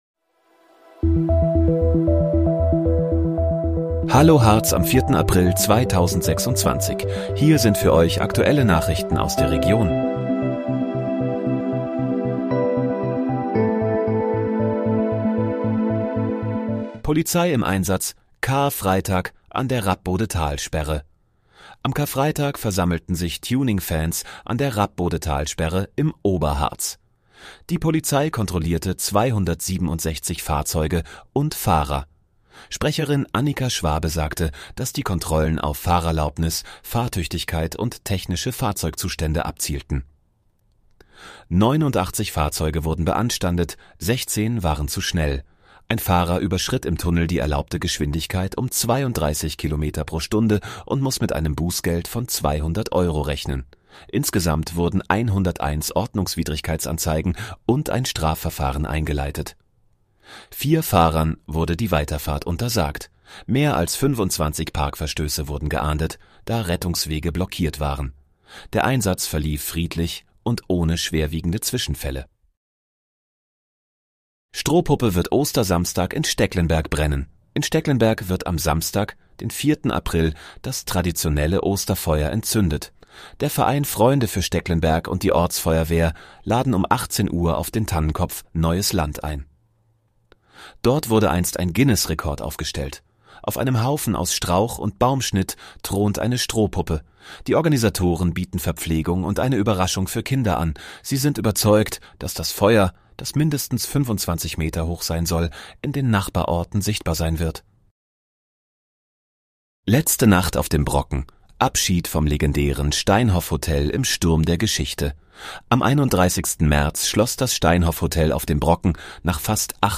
Hallo, Harz: Aktuelle Nachrichten vom 04.04.2026, erstellt mit KI-Unterstützung